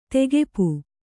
♪ tegepu